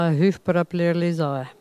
Elle crie pour appeler les oies